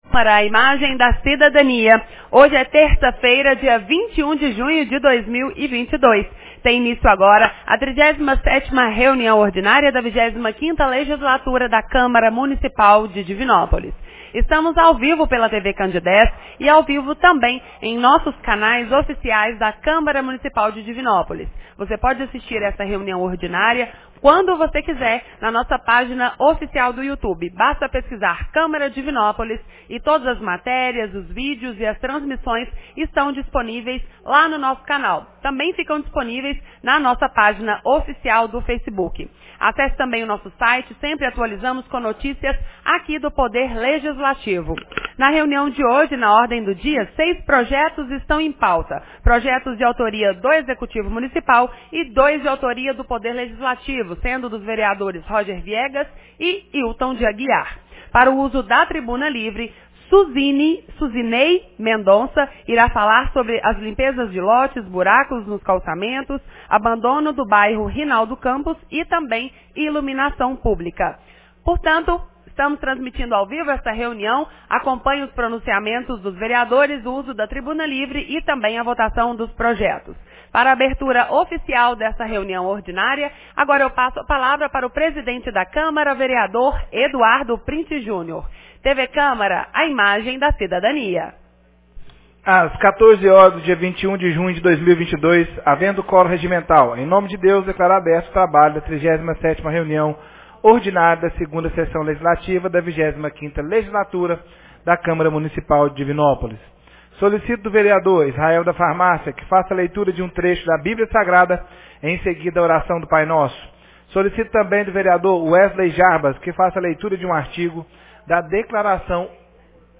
37ª Reunião Ordinária 21 de junho de 2022